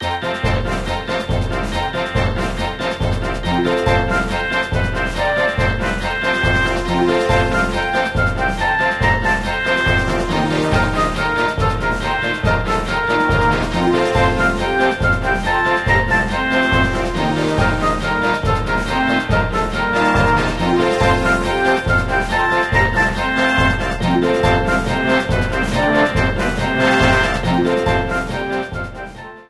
Ripped from the game
applied fade-out